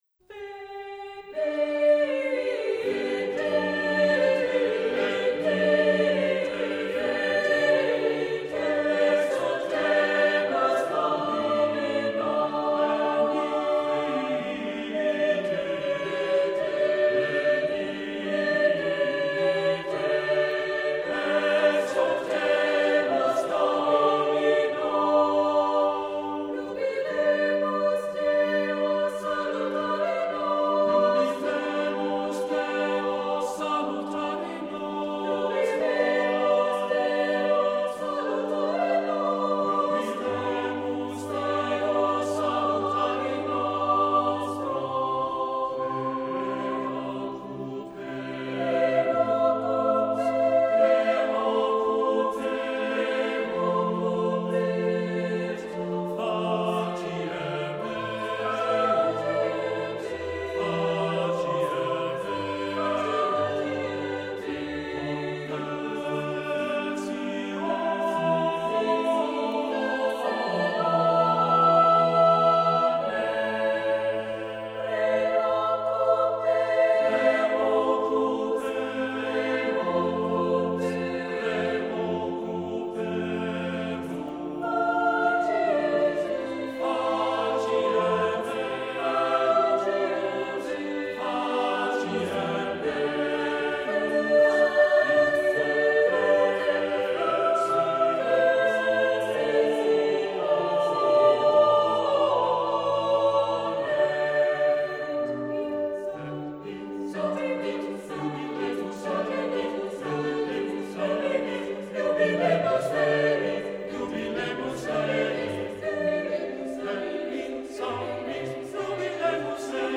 Voicing: SSATB